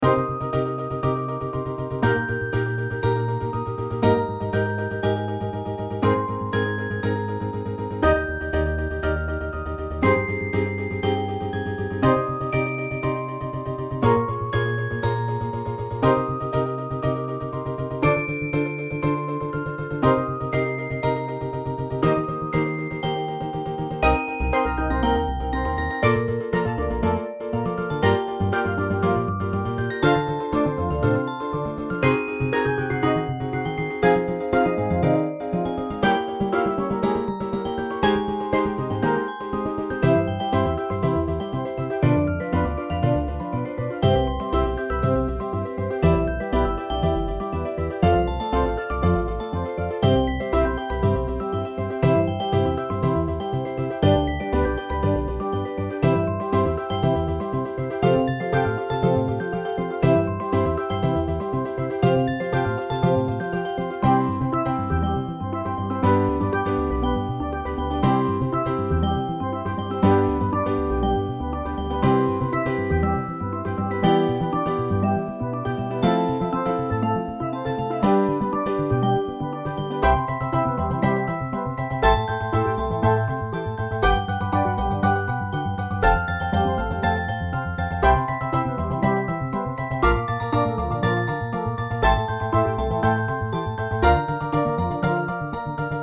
ミュージックボックス、スティールドラム、アコースティックベース、ピアノ
種類BGM